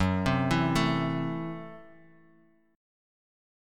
F#m7b5 Chord
Listen to F#m7b5 strummed